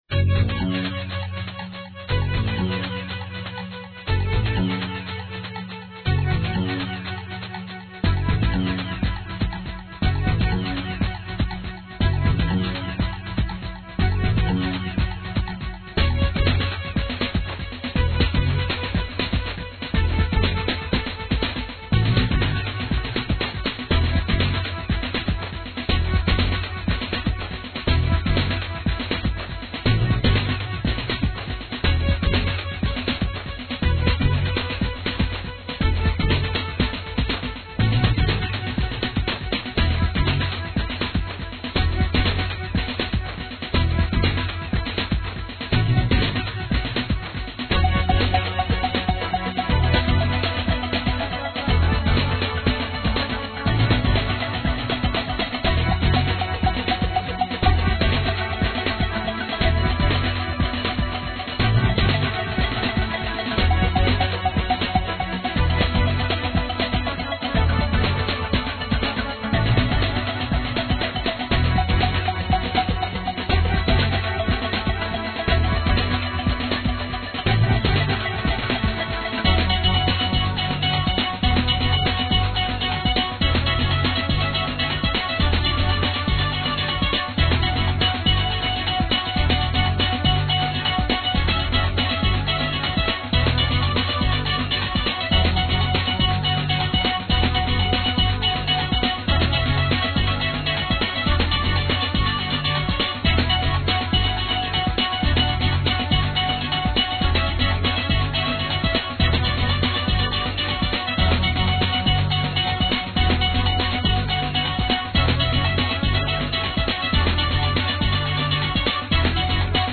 dance/electronic
IDM